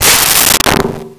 Metal Strike 01
Metal Strike 01.wav